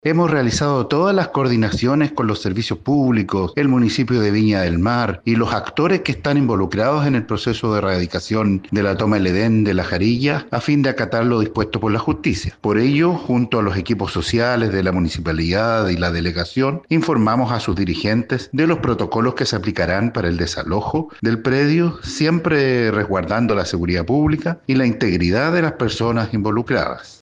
Al respecto, el Delegado Presidencial Regional de Valparaíso, Yanino Riquelme, declaró que ya le informaron a los dirigentes los protocolos que se aplicarán para llevar a cabo el desalojo.